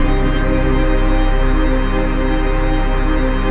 DM PAD2-90.wav